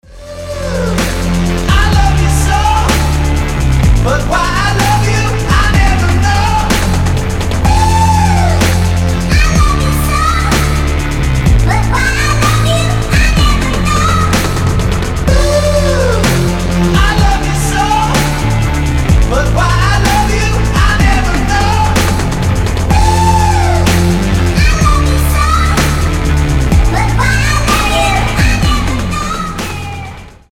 рэп , детский голос , хип-хоп